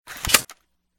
slide.ogg